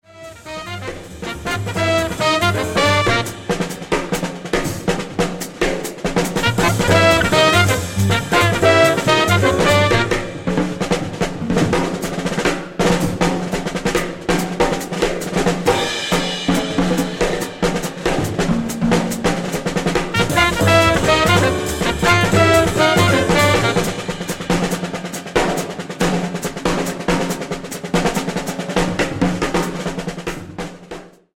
An upbeat, playful, thrill of a composition!
His time is simply incredible, always smooth and swinging.
Eight-measure drum solo intro